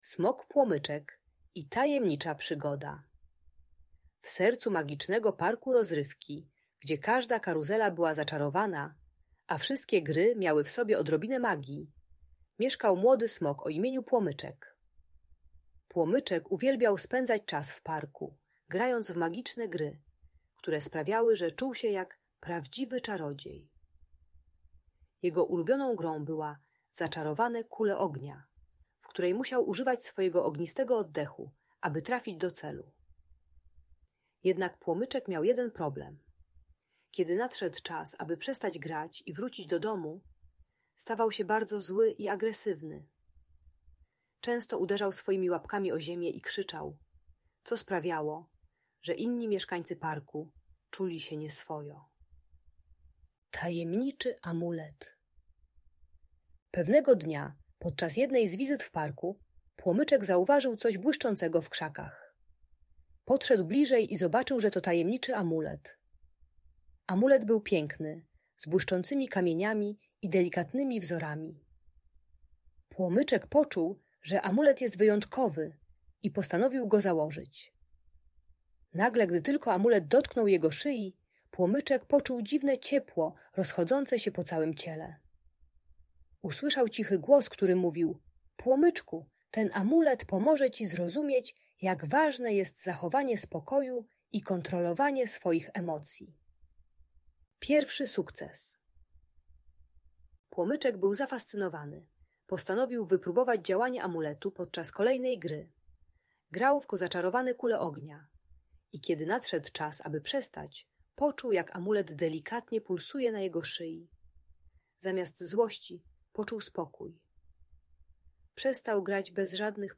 Ta audiobajka o buncie i agresji uczy techniki głębokiego oddychania, aby uspokoić się zamiast krzyczeć i tupać.